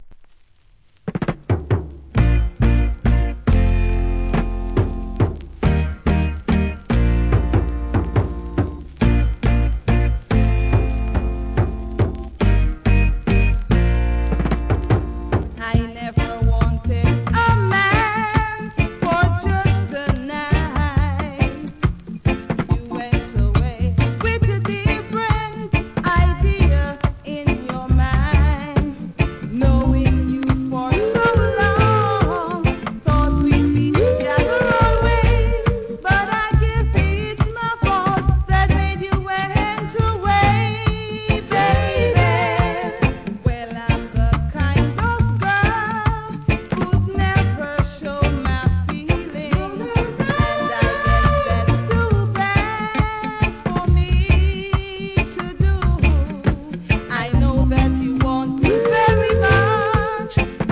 REGGAE
GOODフィメール・ヴォーカル